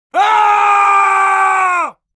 Man-screaming-sound-effect.mp3